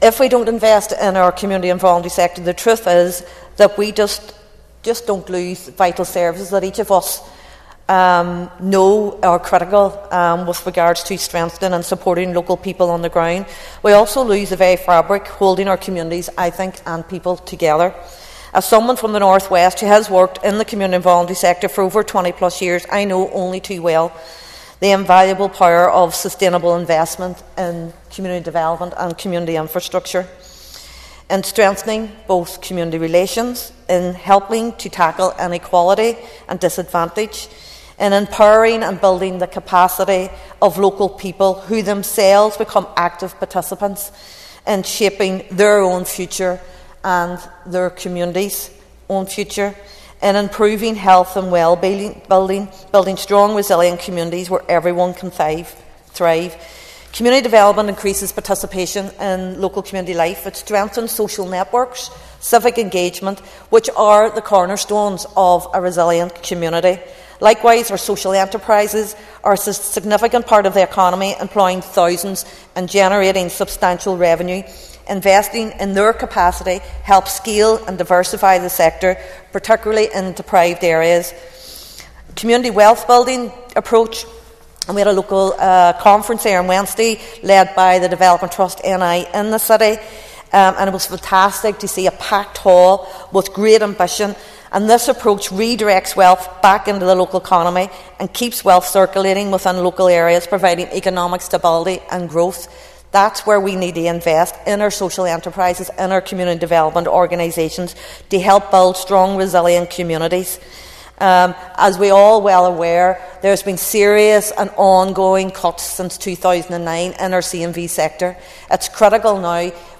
Foyle MLA Ciara Ferguson told the Assembly this week that such funding enables people make decisions in their own communities and shape their own future.